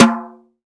Brush Tom Hi.wav